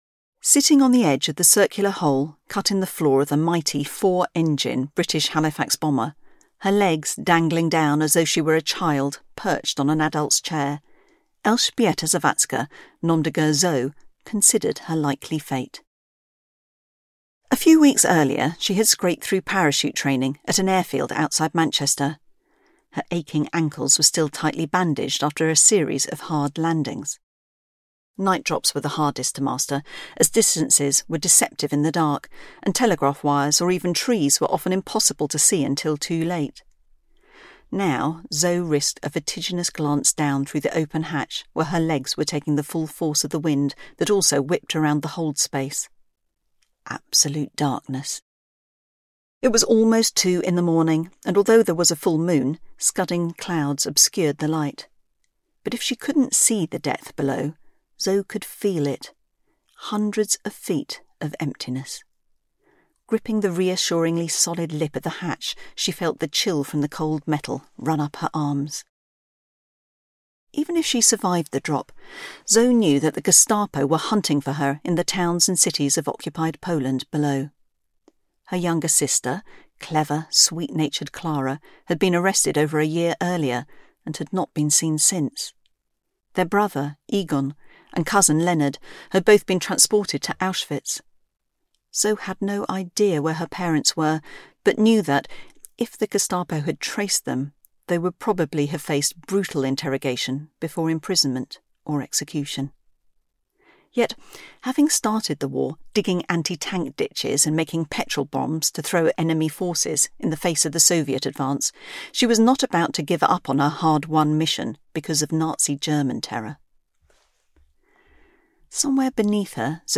Audiobook sample
Agent-Zo-Prologue-small.mp3